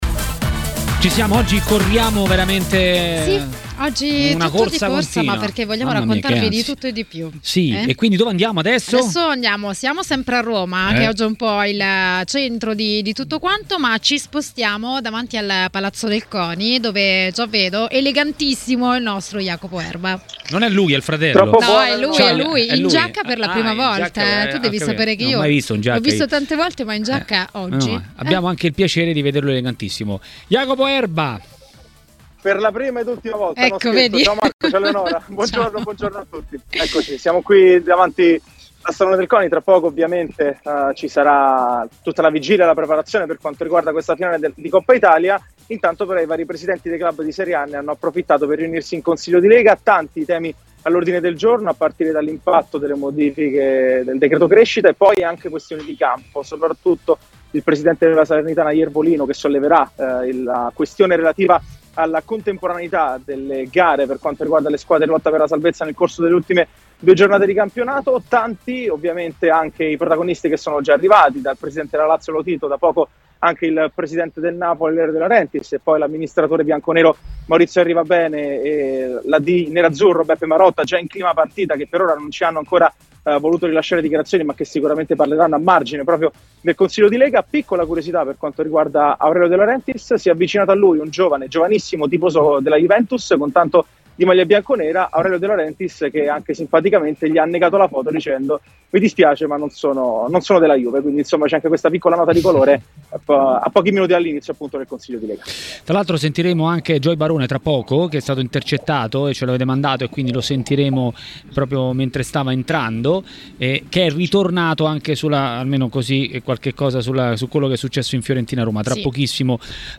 Il giornalista Mario Sconcerti a TMW Radio, durante Maracanà, ha commentato i temi del giorno.